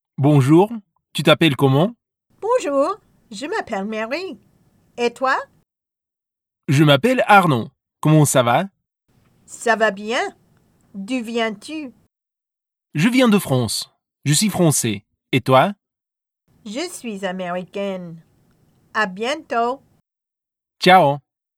Listen to this short conversation in French about two people meeting for the first time.
mod2top1_wu_conversation.wav